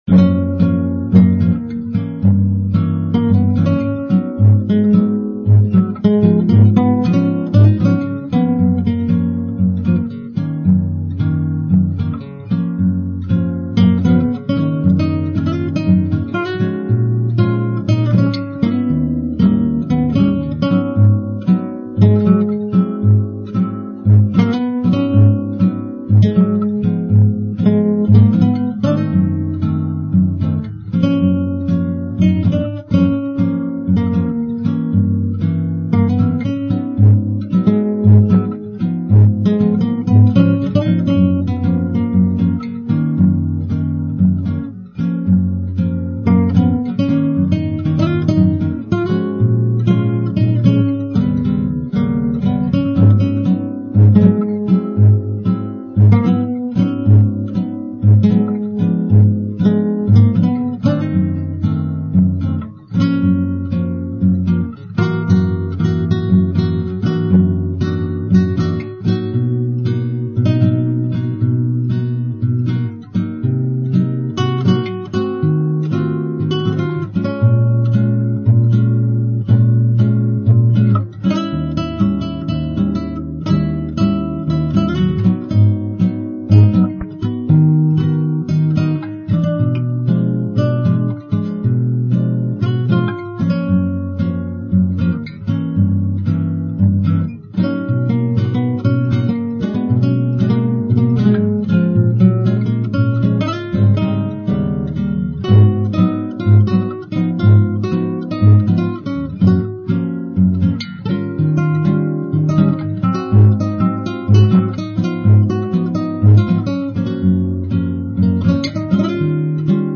SPAZIO BOSSANOVA
La tensione armonica creata nella prima battuta dagli accordi G7 e G7/5+, trova risoluzione nel successivo accordo C7+ al quale seguono altri due accordi (C#-7/5- e E°) dotati di analoga tensione che a loro volta risolvono nel B-7. Nella battuta 6 eseguiamo un A-7/9 come abbellimento del A-7. Di gradevole effetto accustico è il passaggio E13 - E7/5+ -A7 a cavallo tra le battute 10 e 11.
Da evidenziare infine il particolare effetto armonico dell'accordo A-6/9 suonato con tale diteggiatura.